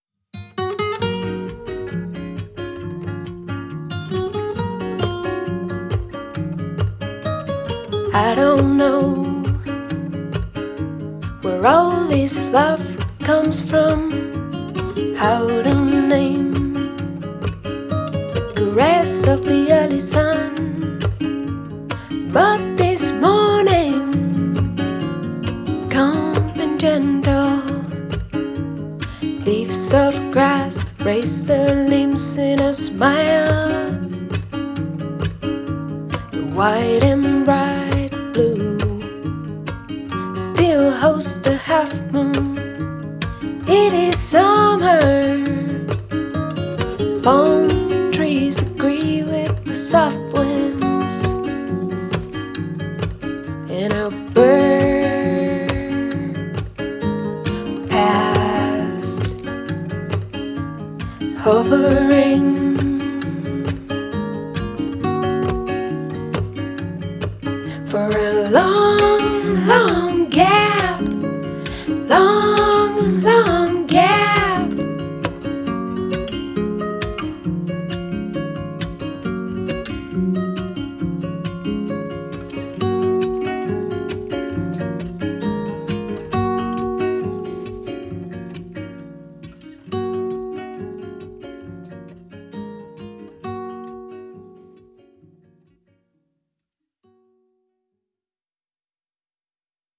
Voz y guitarra acústica
Guitarra acústica
Grabado en Cholula, México, 2022.